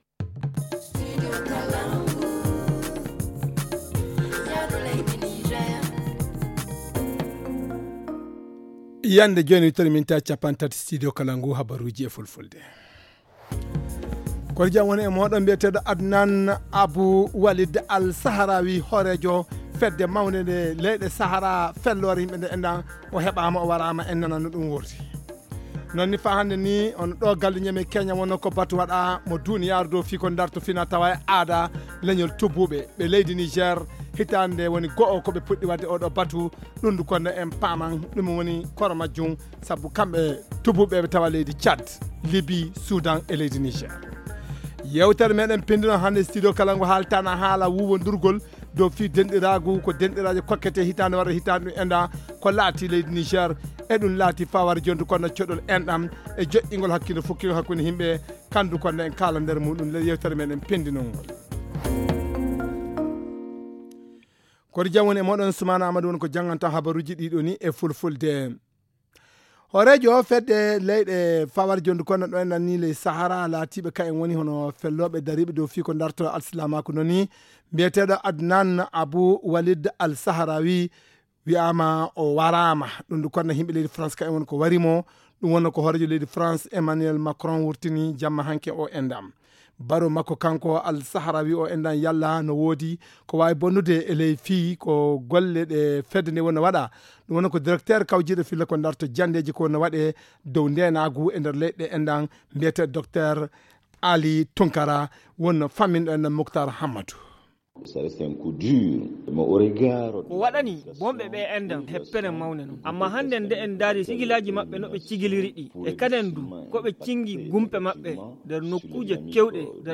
Le journal du 16 septembre 2021 - Studio Kalangou - Au rythme du Niger